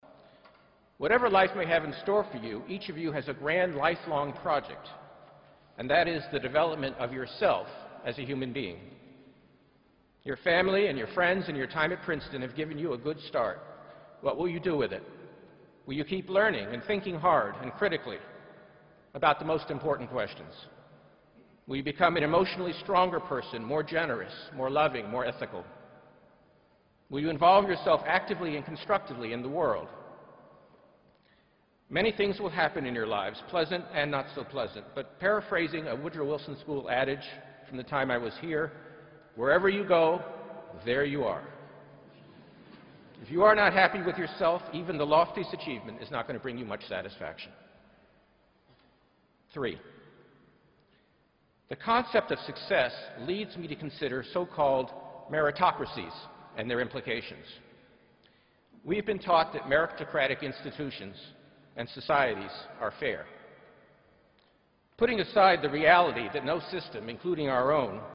公众人物毕业演讲第301期:本伯南克2013普林斯顿(5) 听力文件下载—在线英语听力室